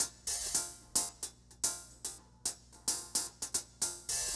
RemixedDrums_110BPM_31.wav